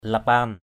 /la-pa:n/ (cv.) lipan l{pN 1.
lapan.mp3